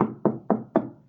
sounds_door_knock_01.ogg